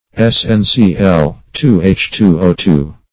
Stannous \Stan"nous\ (-n[u^]s), a. (Chem.)